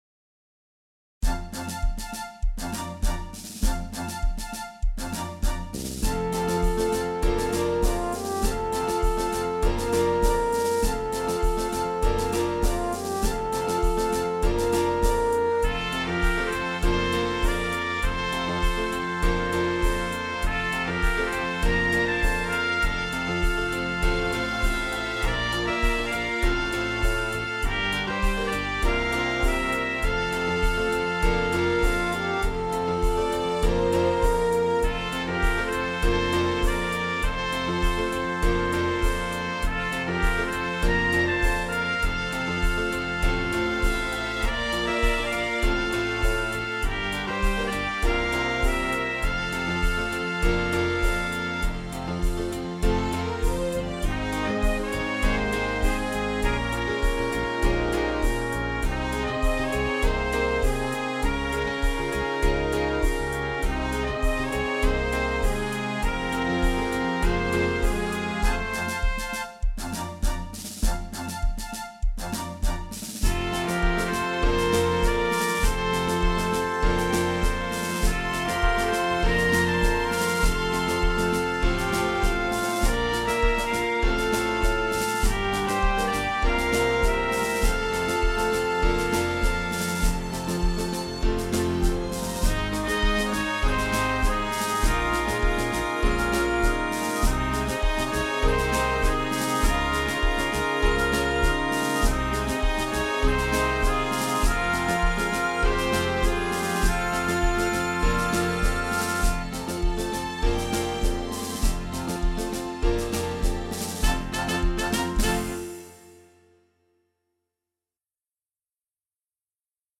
Complete arrangement